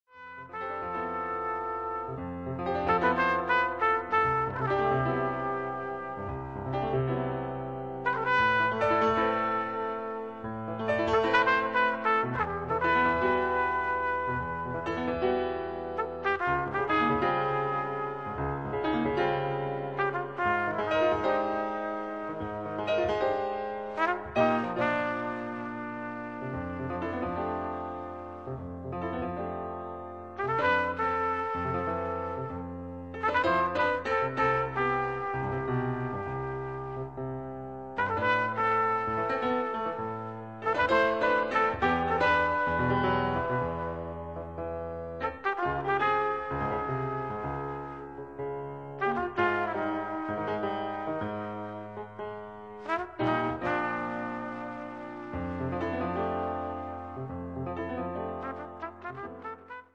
Composer, conductor, band leader, jazz pianist